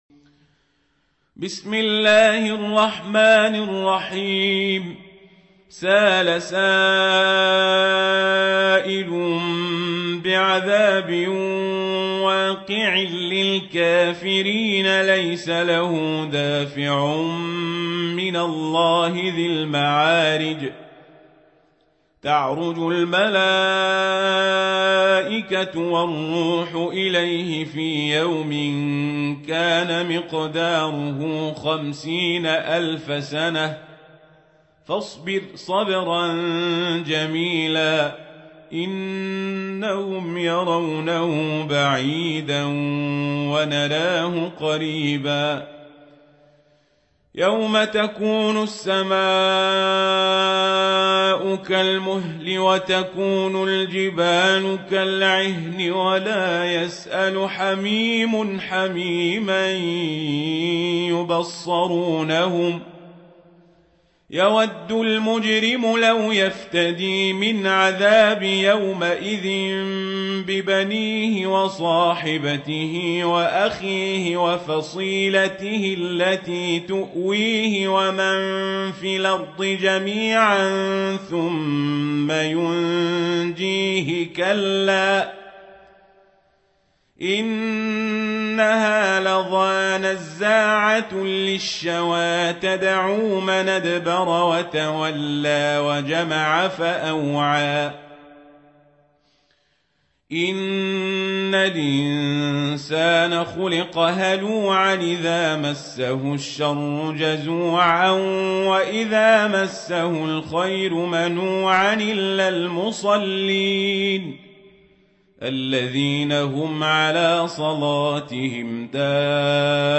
سورة المعارج | القارئ عمر القزابري